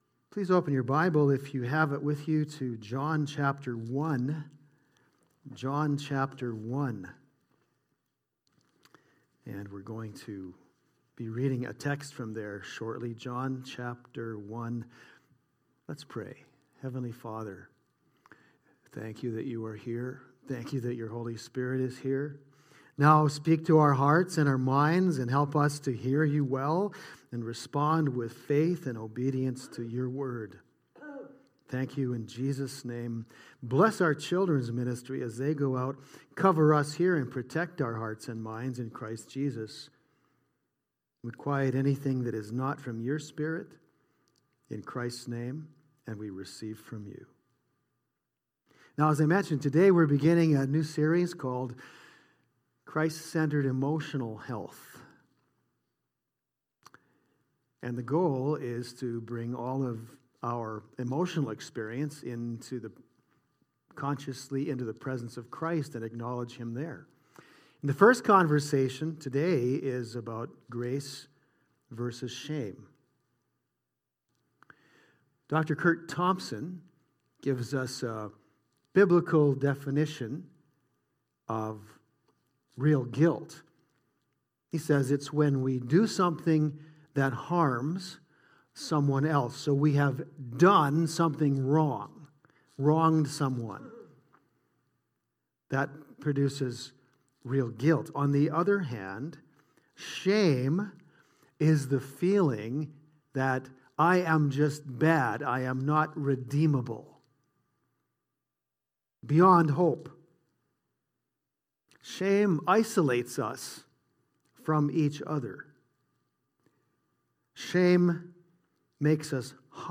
The Word’s inexhaustible grace overwhelms the exhaustion of shame. Teaching is from the text John 1:14-17.